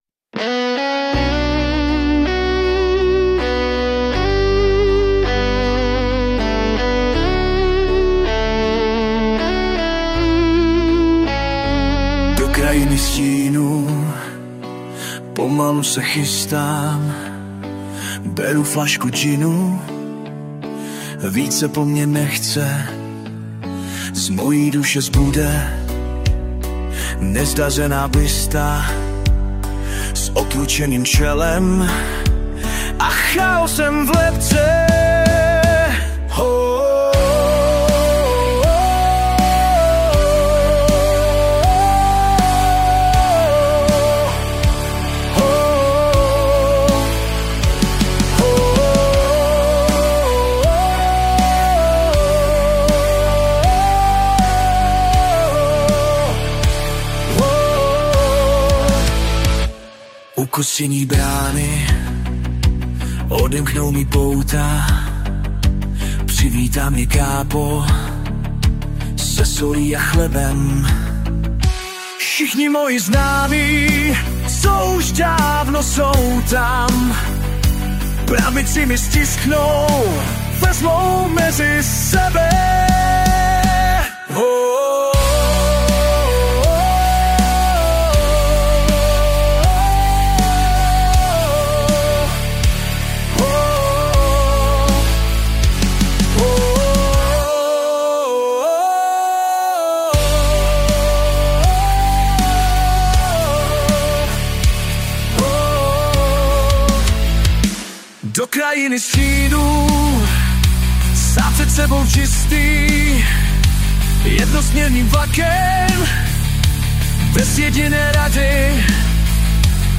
hudba a zpěv: AI
Upřímné: je to strašně umělý, na "první zvuk" poznáš AI... na jedno brdo.